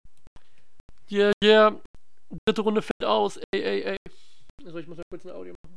Deine Stimme ist aber auch sexy.